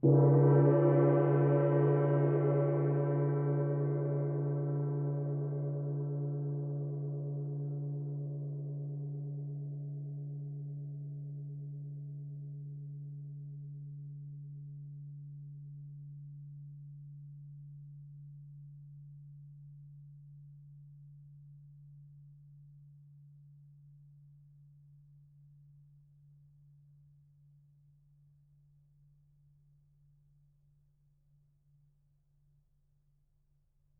gongHit_mf.wav